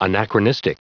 Prononciation du mot anachronistic en anglais (fichier audio)
Prononciation du mot : anachronistic